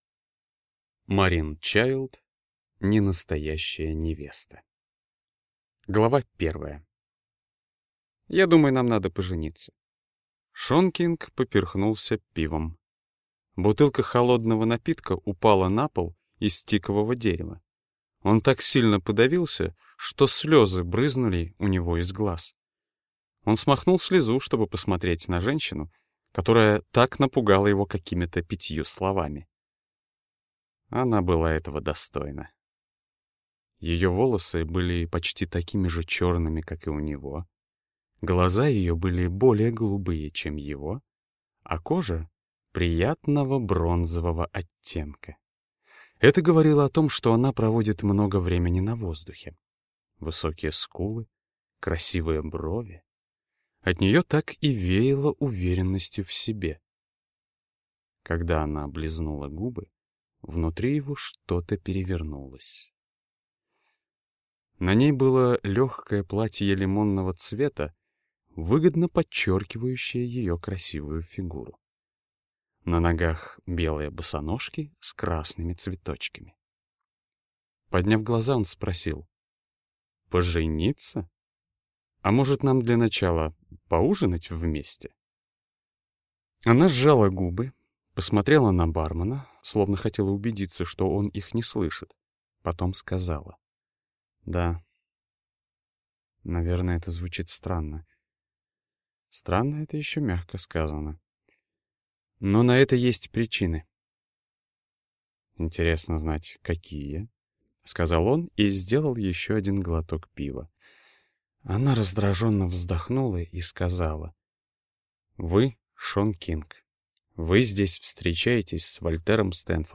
Аудиокнига Ненастоящая невеста | Библиотека аудиокниг